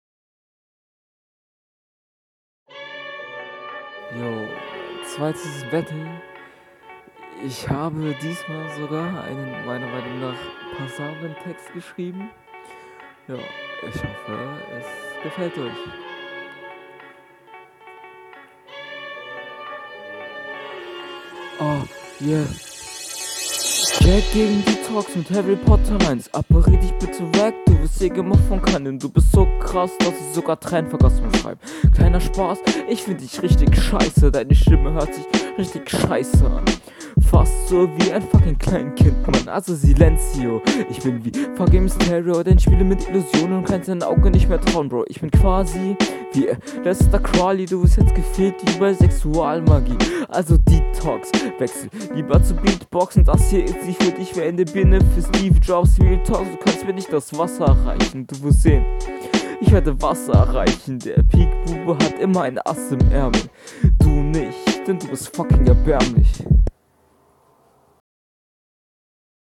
Joa, ontake und aufgenommen mit Kopfhörern (?) grenzt die Möglichkeiten natürlich ein.
Checke deine Flowansätze, wirkst aber noch sehr unsicher auf dem Beat.